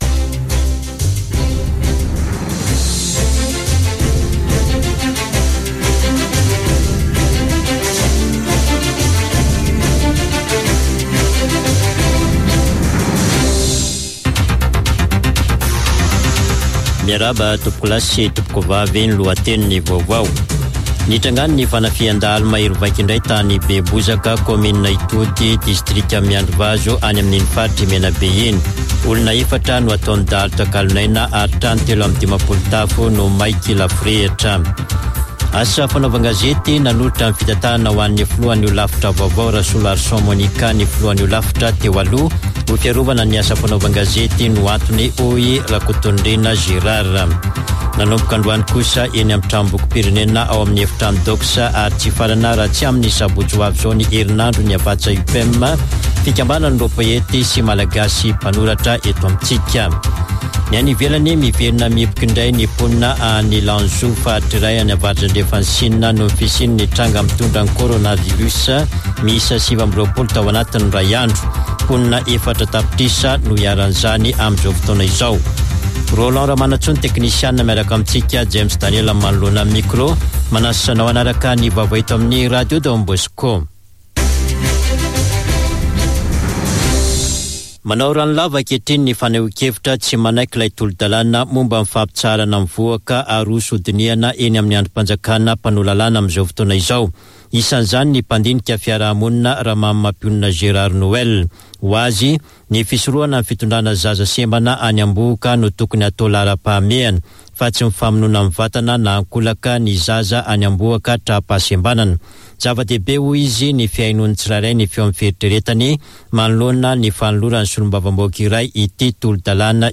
[Vaovao hariva] Talata 26 oktobra 2021